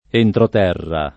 [ entrot $ rra ]